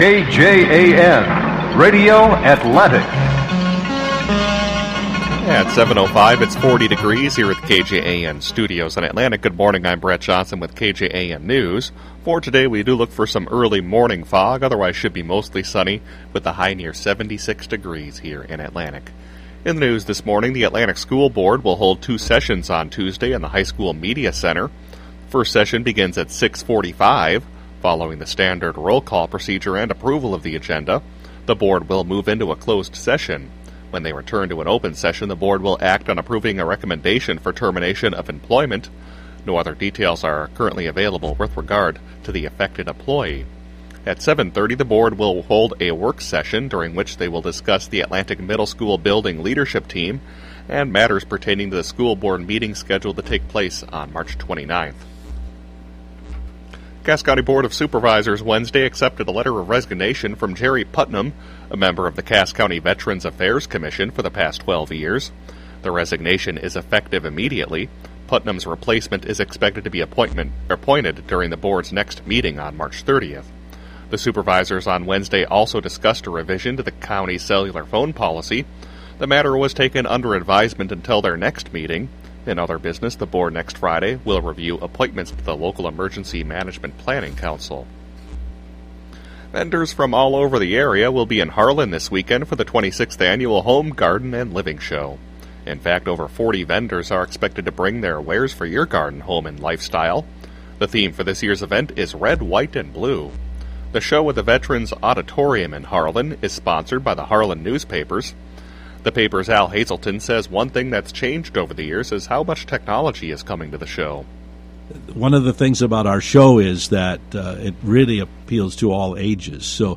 7AM Newscast 3-24-12